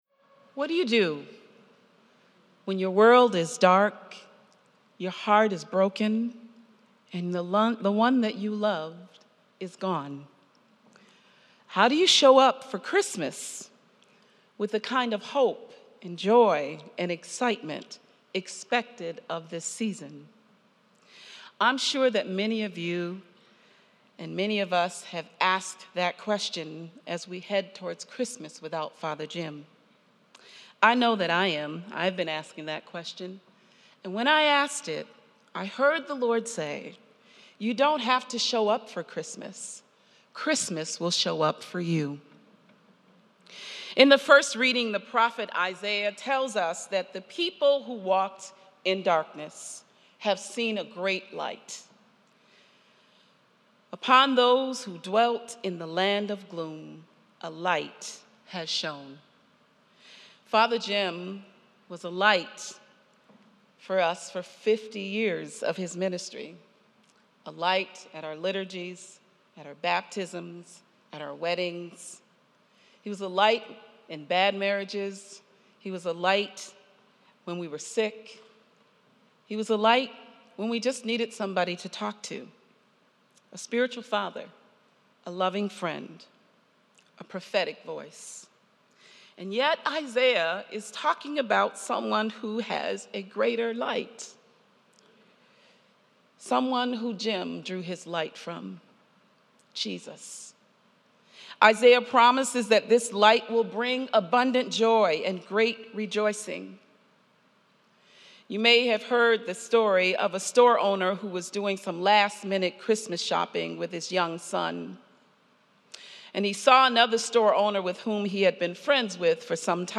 Christmas Eve Mass 2024